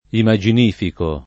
vai all'elenco alfabetico delle voci ingrandisci il carattere 100% rimpicciolisci il carattere stampa invia tramite posta elettronica codividi su Facebook imaginifico [ ima J in & fiko ] o immaginifico agg.; pl. m. ‑ci